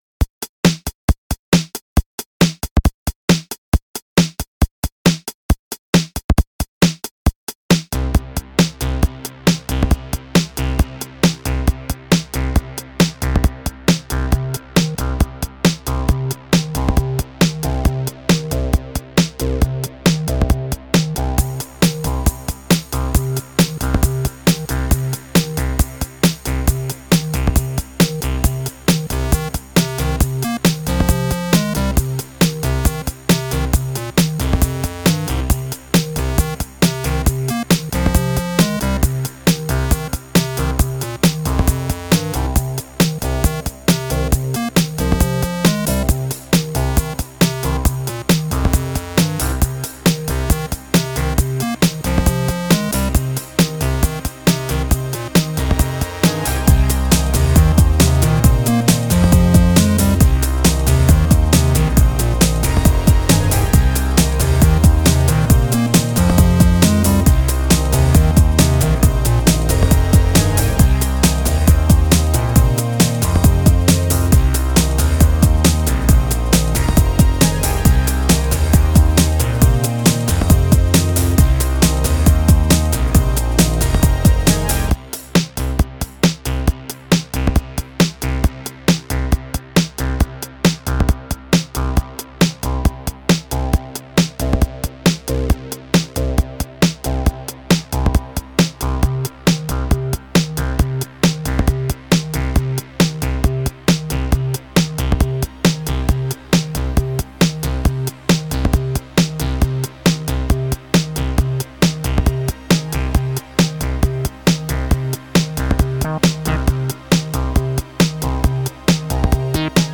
a breaks track with a bit of lowfi fun mixed in.